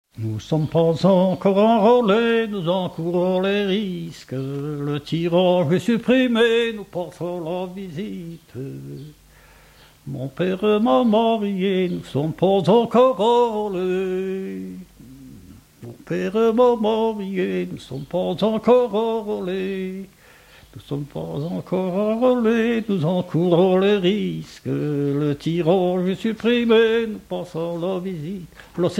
Chanson de conscrits
Chants brefs - Conscription
Genre strophique